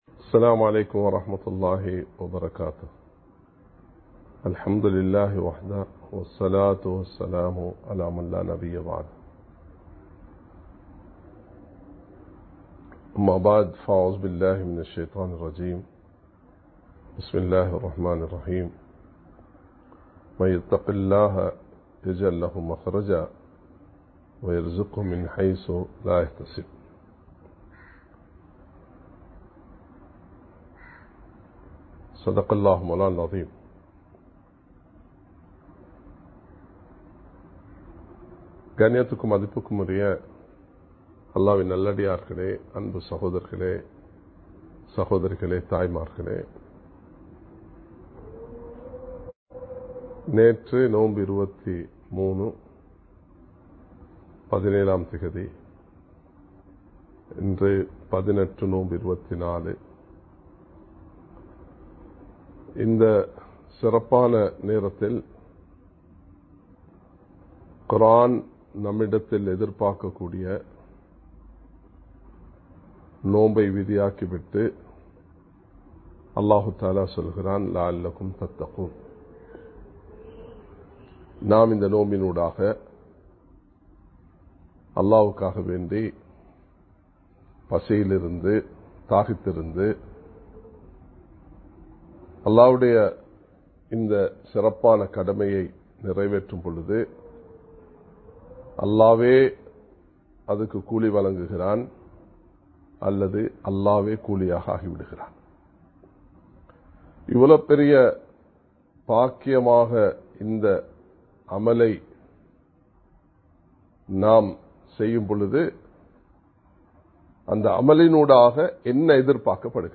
தக்வா (Part - 2) (Fear of Allah) | Audio Bayans | All Ceylon Muslim Youth Community | Addalaichenai
Live Stream